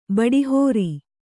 ♪ baḍi hōri